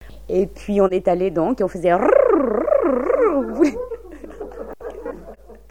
Le cris des routoutous